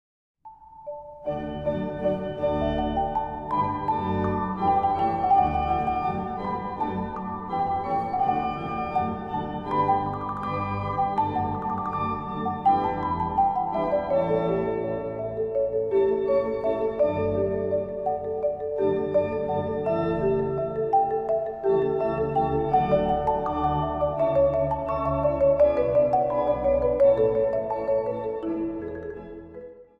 Instrumentaal | Marimba